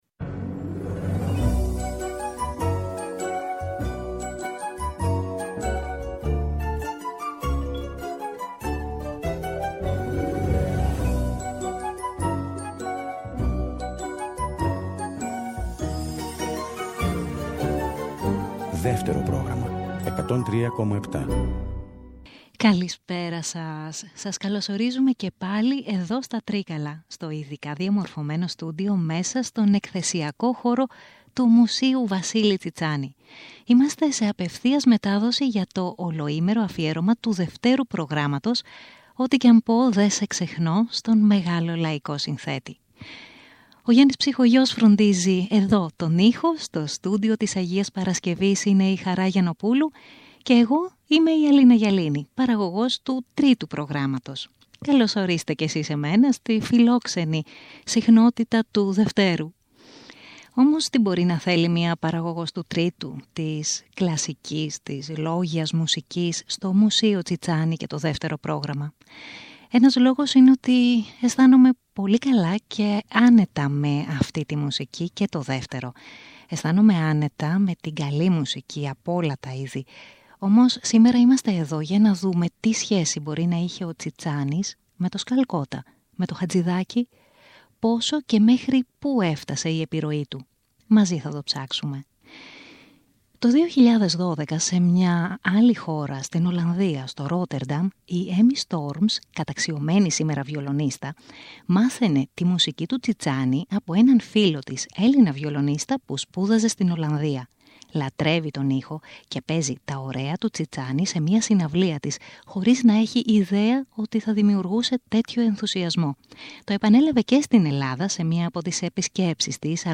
ζωντανά από το Μουσείο Τσιτσάνη